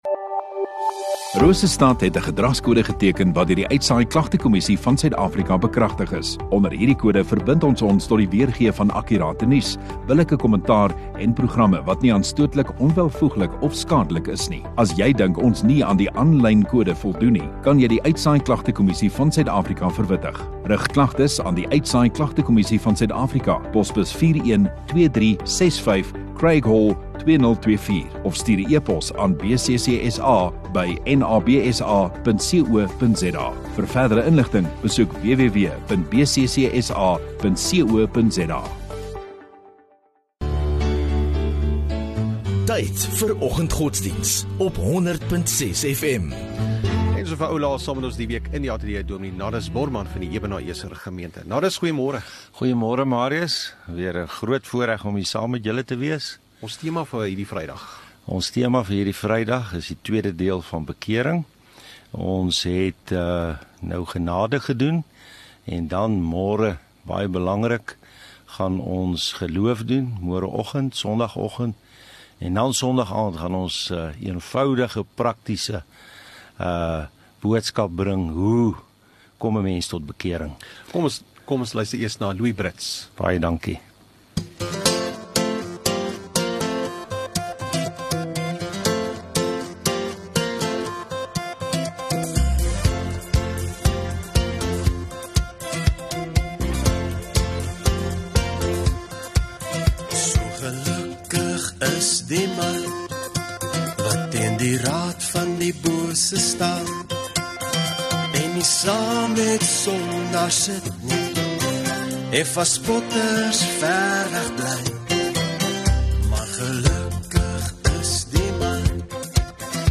18 Oct Vrydag Oggenddiens